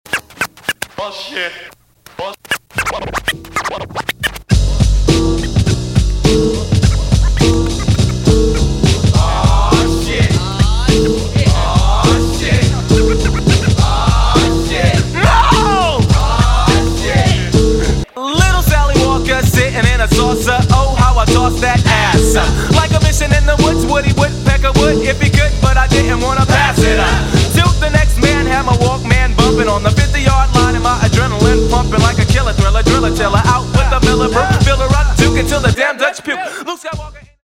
• Качество: 320, Stereo
Хип-хоп
забавные
веселые
jazz rap
Забавный хип-хоп Западного побережья